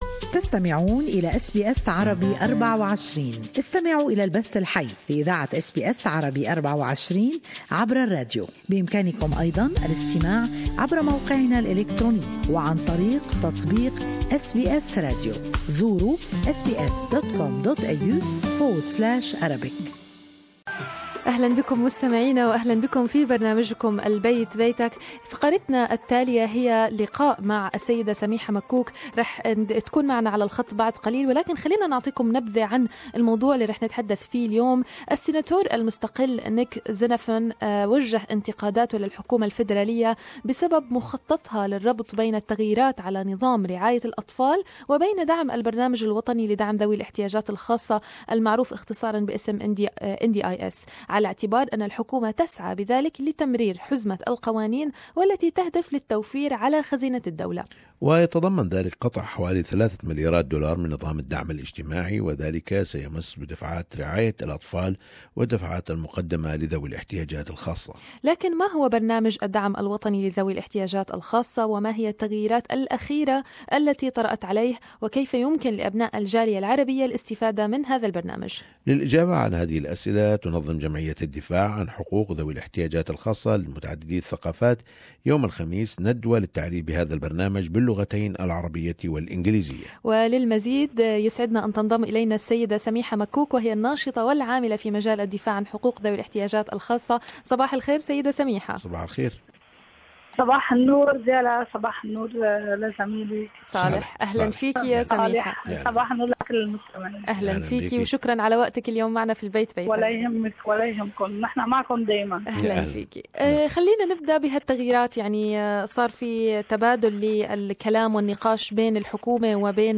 اللقاء